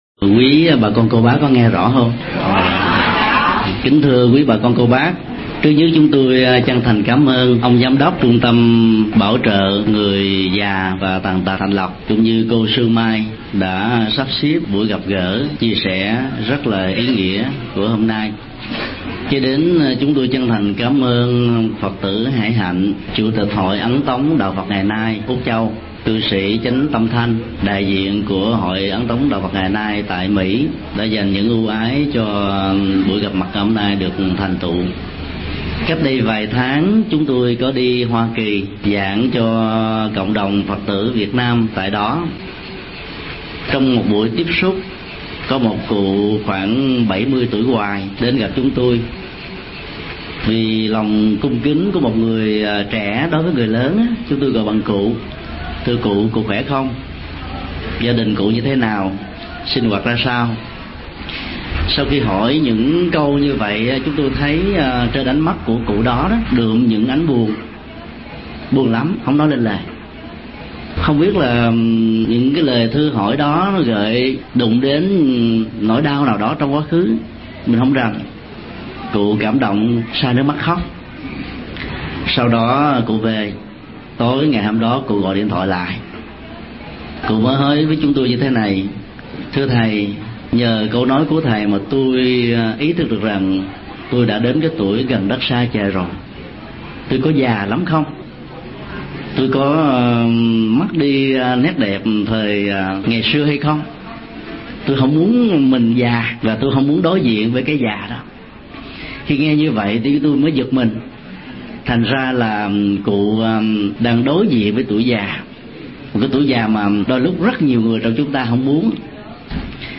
Đối diện tuổi già – Mp3 Thầy Thích Nhật Từ Thuyết Giảng
Mp3 Pháp Thoại Đối diện tuổi già – Thầy Thích Nhật Từ Trung Tâm Bảo Trợ Người Già và Tàn Tật Thạnh Lộc, ngày 23 tháng 1 năm 2005